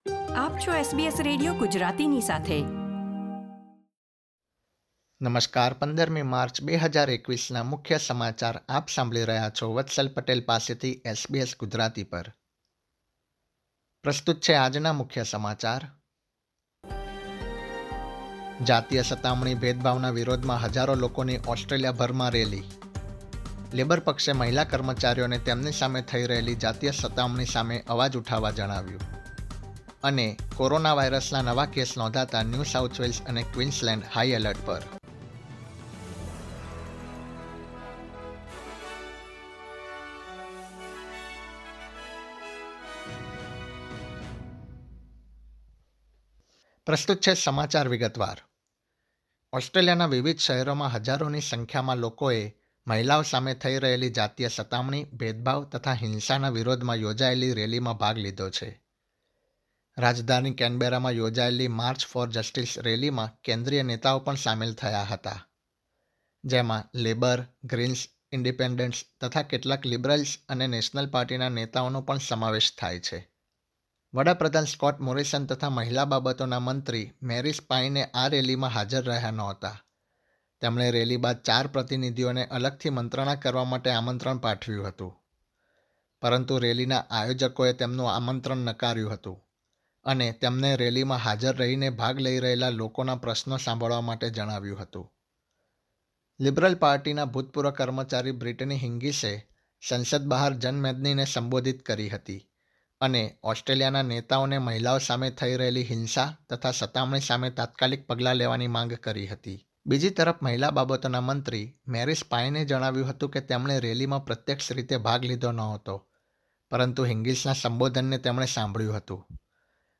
gujarati_1503_newsbulletin.mp3